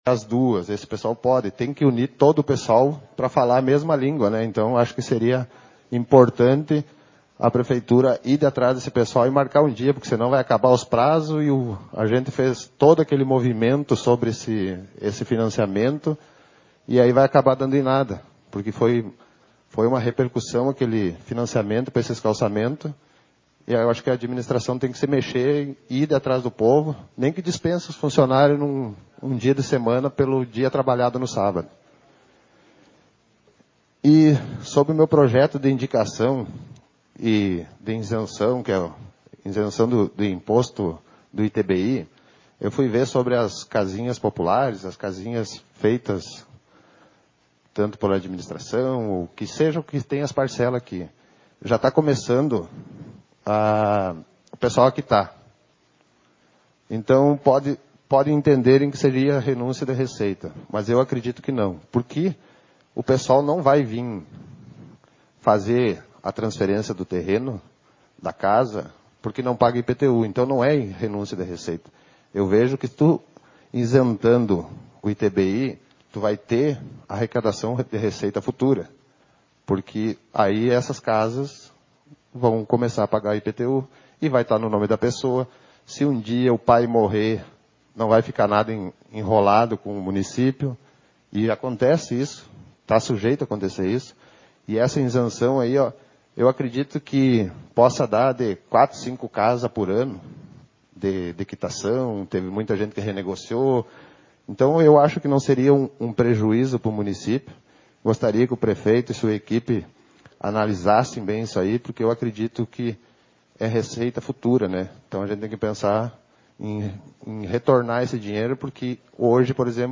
Sessão Ordinária 36/2021